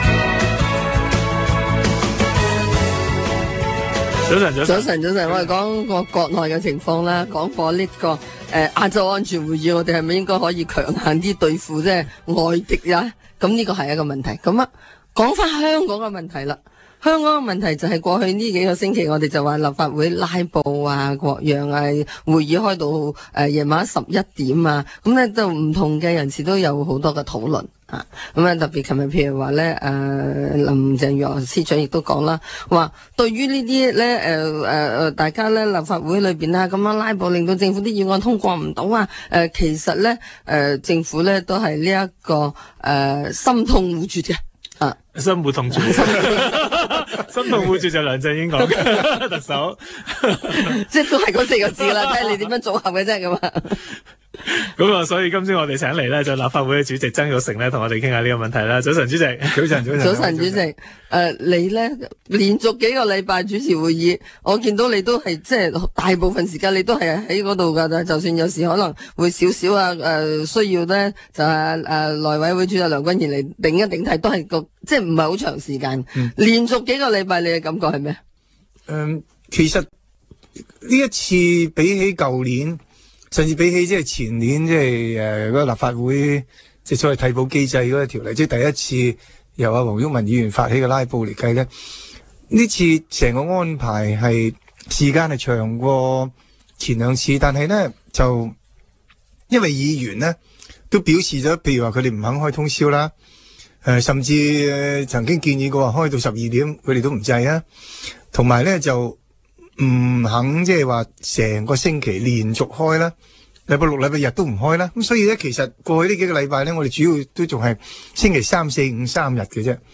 商業電台《政好星期天》訪問